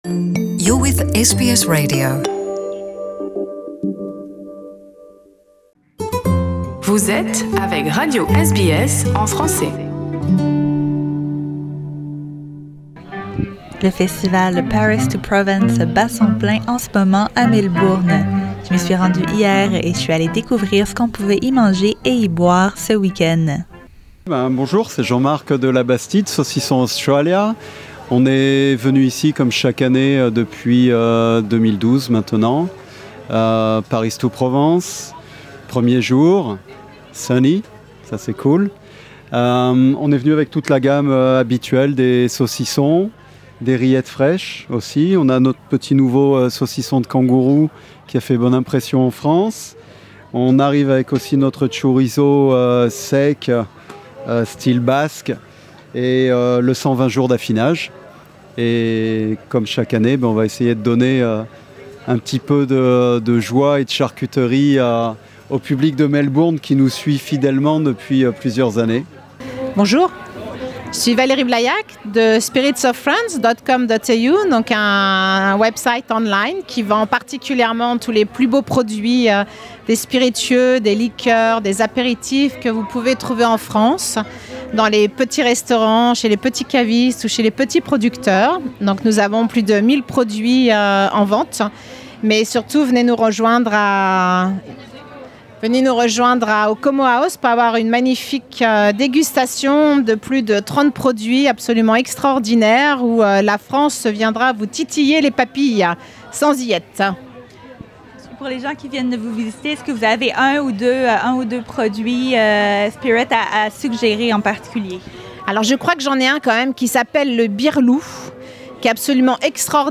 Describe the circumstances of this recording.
Nous sommes allés rencontrer quelques exposants du volet culinaire du festival Paris to Provence, qui a lieu ce week-end à Melbourne. Au menu : saucisson de kangourou, fondue, rosé, pizza au canard, fromage, crêpes, raclette et croissants.